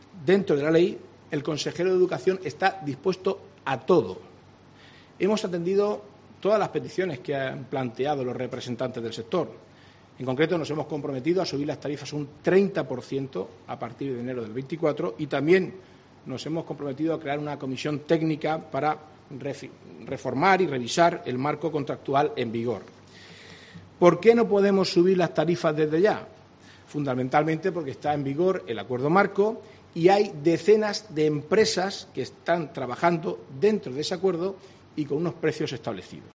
Marcos Ortuño, portavoz del Gobierno Regional